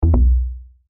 lose.wav